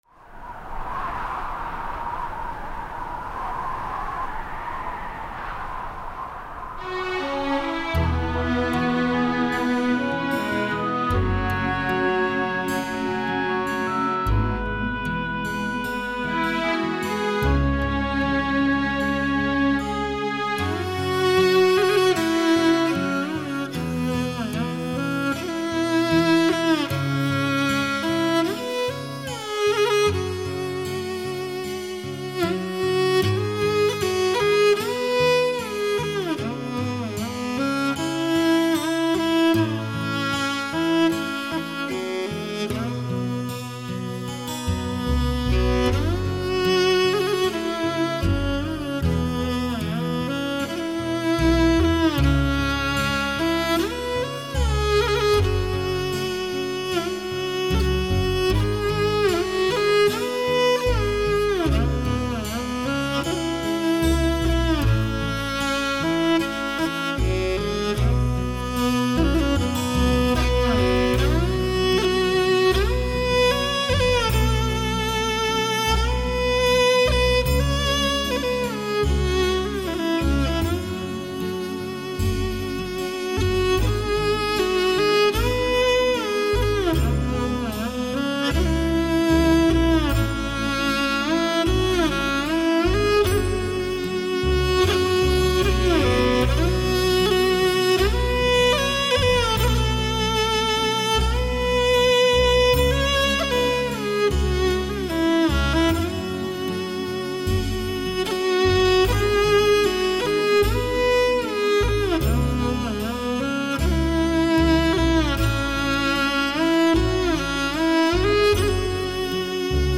宁静·空灵·飘逸 美妙天籁般的天堂乐声
仿佛来自仙界 醉听极美空灵的天籁之音